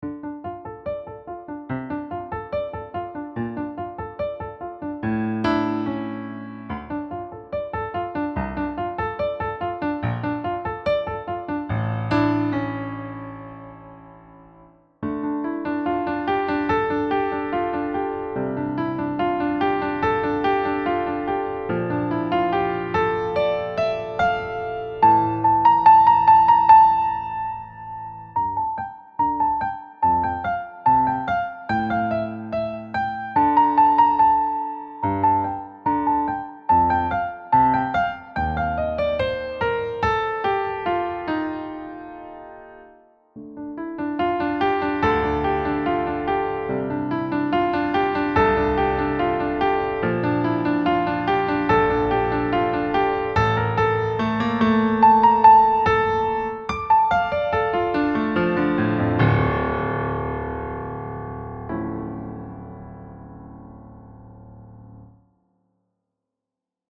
Key: D minor
Time Signature: 4/4 then into 3/4 for the dramatic ending